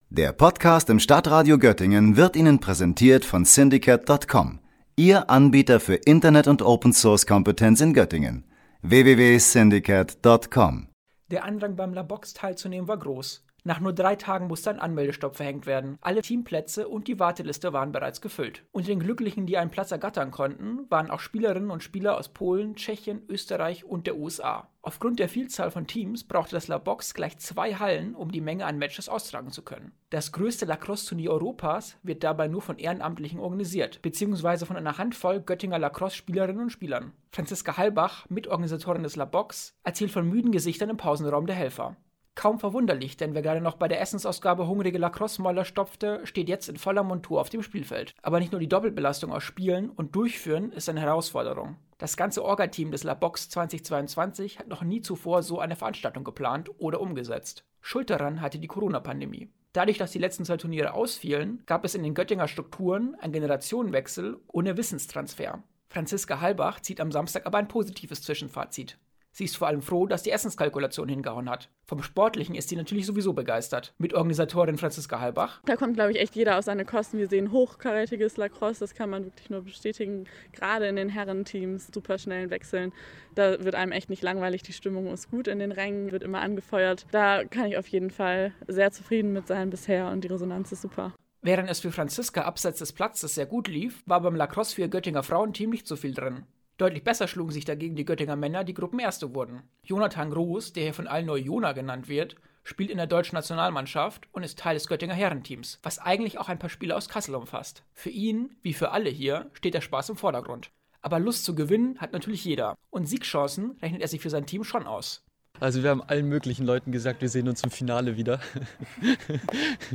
Ein internationaler Hauch zog vergangenes Wochenende in das Felix-Klein-Gymnasium in Göttingen ein. Das größte Lacrosse-Hallentunier Europas, das "LaBox" fand wieder in Göttingen statt. Je zwölf Teams der Frauen und Männer Staffel kämpften um den Gänselieselpokal.